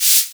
Wu-RZA-Hat 71.wav